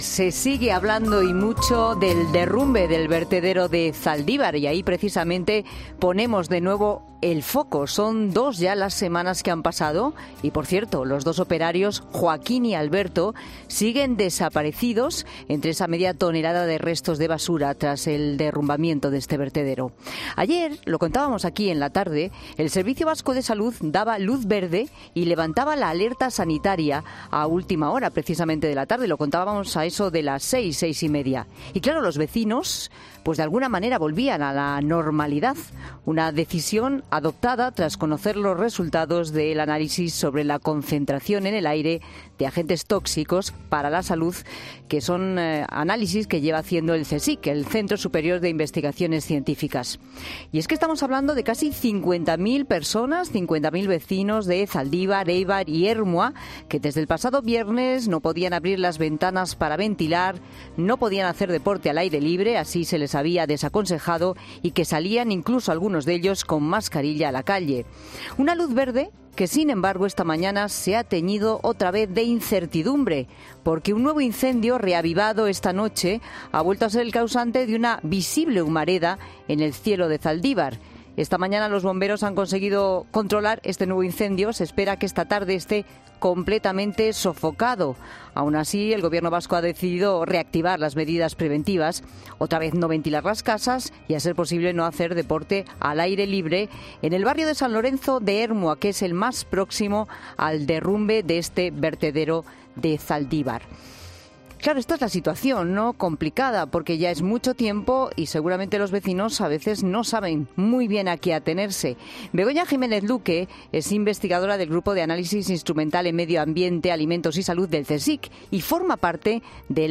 Escucha las valoraciones detalladas de esta experta sobre la calidad del aire y la forma que tienen de actuar.